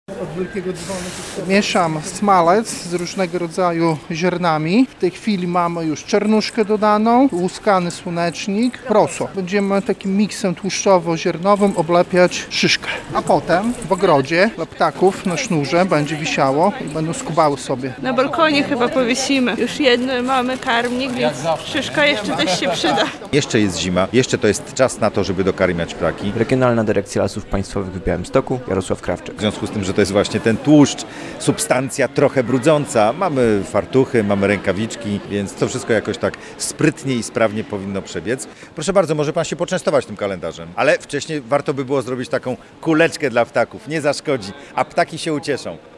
Smalec, ziarna, karmniki i szyszki - w galerii Atrium Biała w Białymstoku odbyły się warsztaty z zimowego dokarmiania ptaków.
relacja